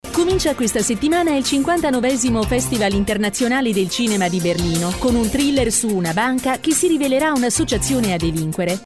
Her articulate, engaging delivery suits commercials, narration, e-learning, and character work – perfect for brands seeking a professional Italian voice actor.
Announcements
Mic Neumann TLM103